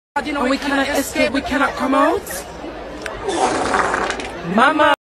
Wet Fart Sound Effect Meme